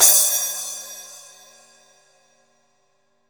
Index of /90_sSampleCDs/Best Service - Real Mega Drums VOL-1/Partition G/CYMBALS
12SPLASH.wav